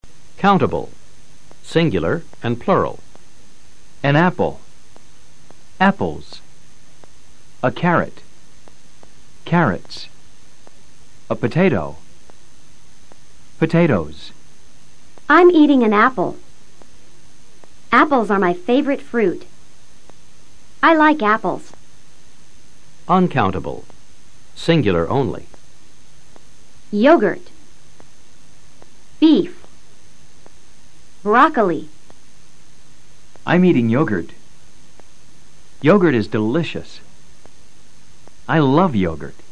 Escucha al profesor leyendo SUSTANTIVOS CONTABLES y NO CONTABLES.